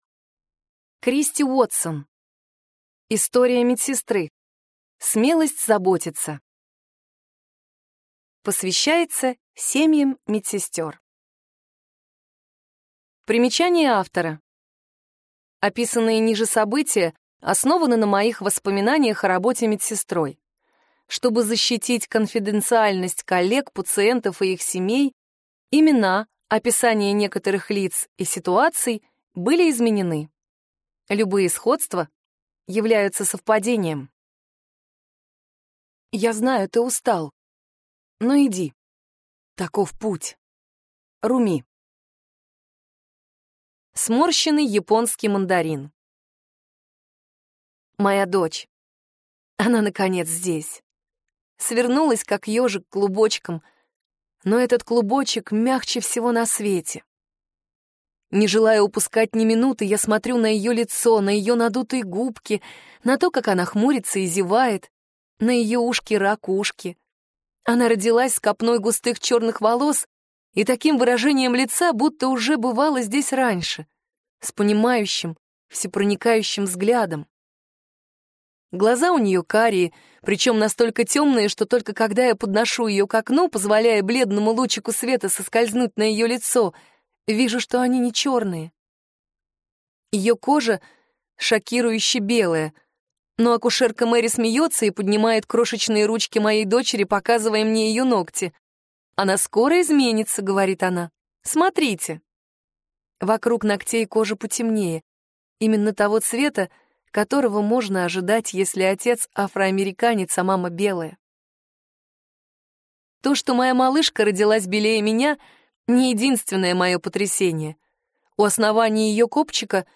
Аудиокнига Истории медсестры. Смелость заботиться | Библиотека аудиокниг